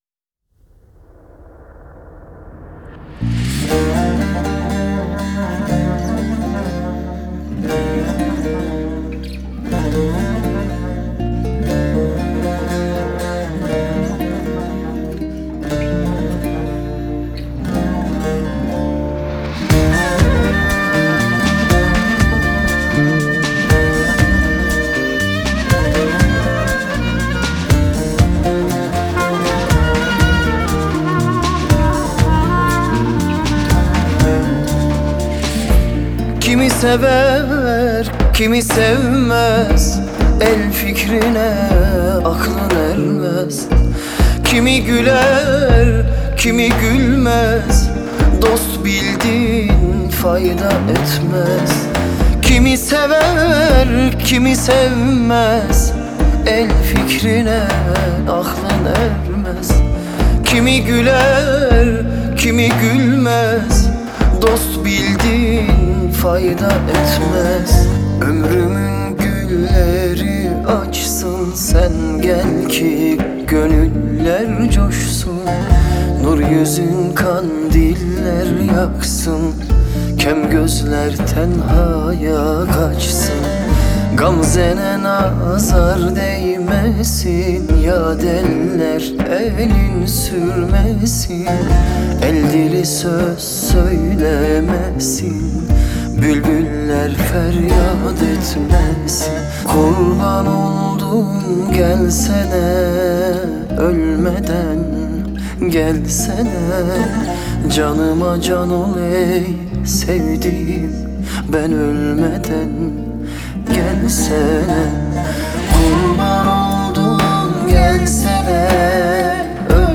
آهنگ ترکیه ای آهنگ غمگین ترکیه ای